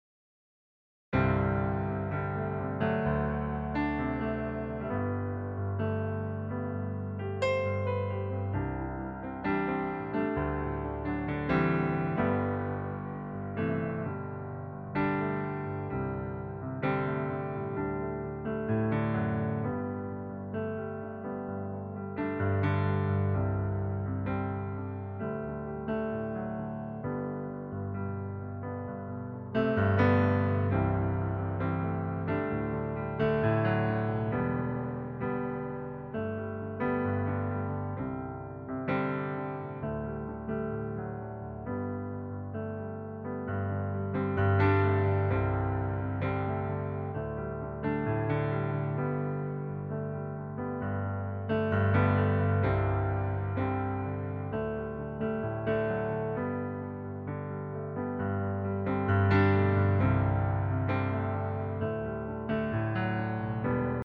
Demo in G-Dur